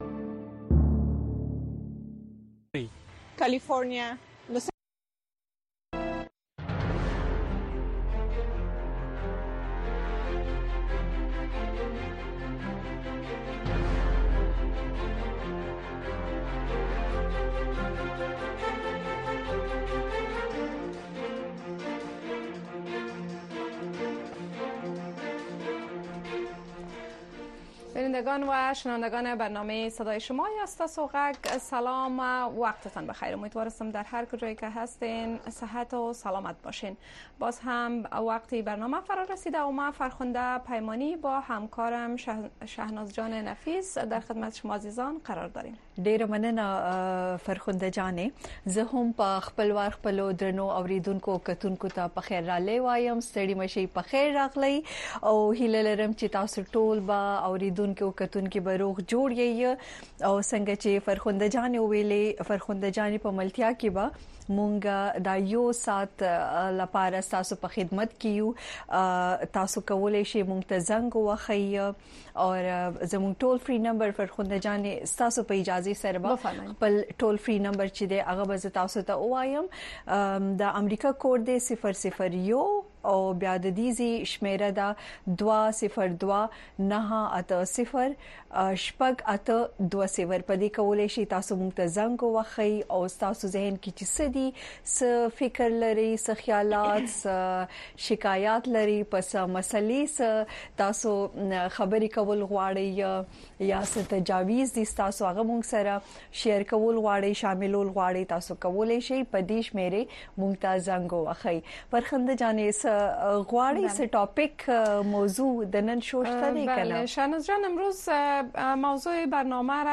دا خپرونه په ژوندۍ بڼه د افغانستان په وخت د شپې د ۹:۳۰ تر ۱۰:۳۰ بجو پورې خپریږي.